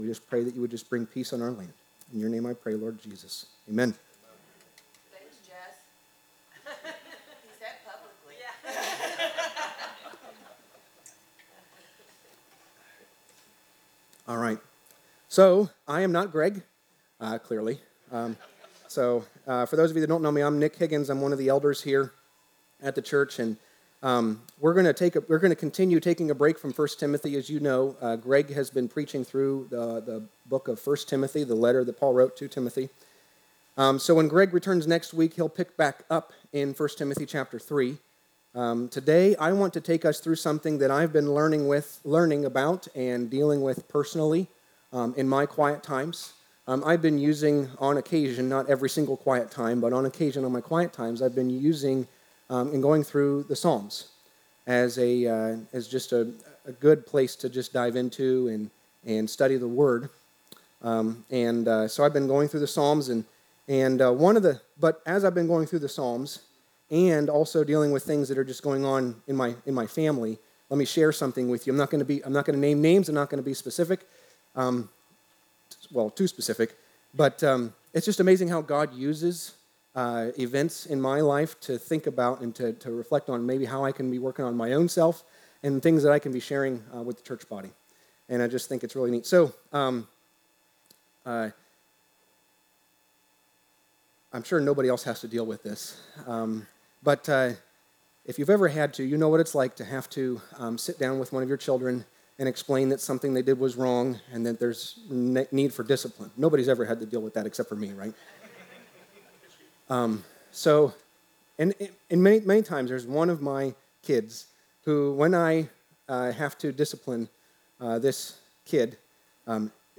Sermons – Darby Creek Church – Galloway, OH
Sermons that are not part of a series